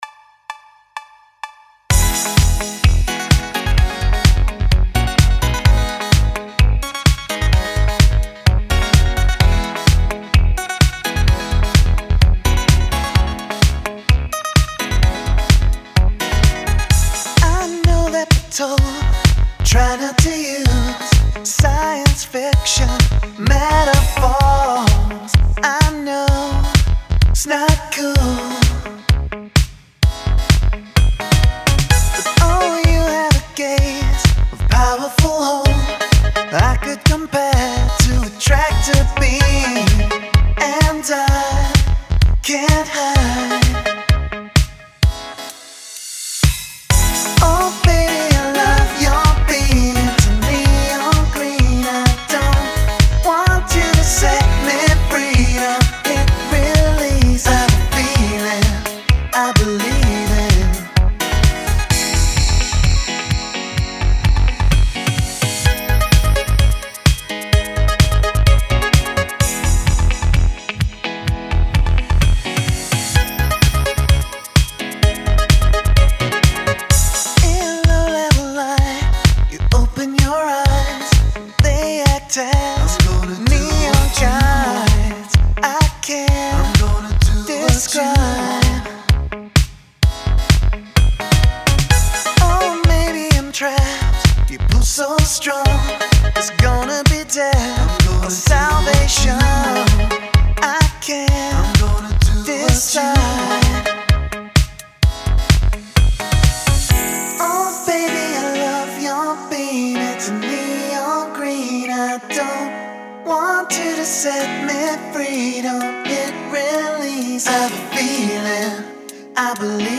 vocals
guitar
keytar, synth